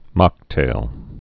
(mŏktāl)